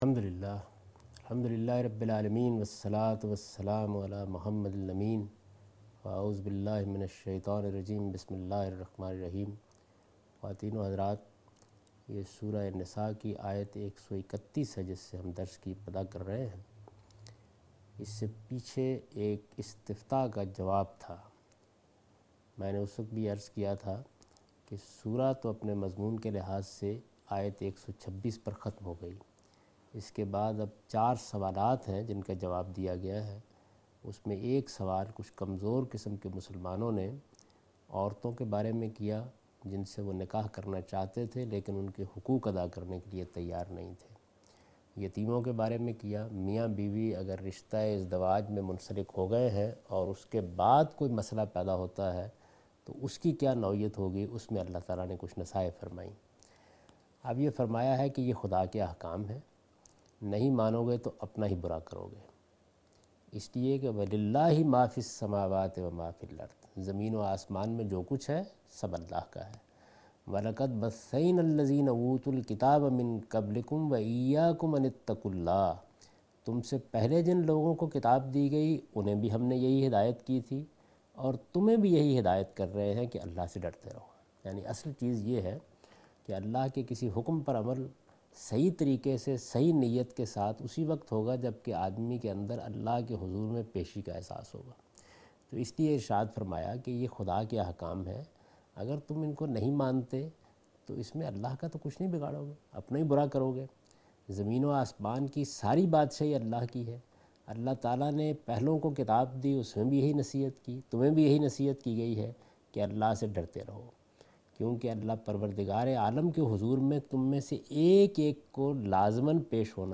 Surah Al-Nisa - A Lecture of Tafseer ul Quran Al-Bayan by Javed Ahmed Ghamidi